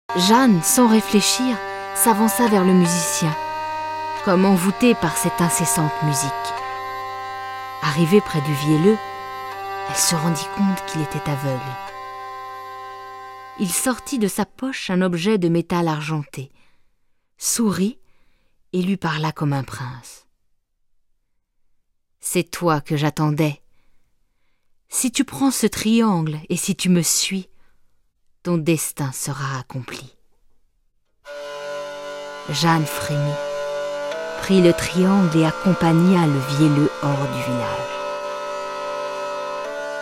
Livres audios
Cliquez ici pour découvrir des exemples de prestations pour des livres audios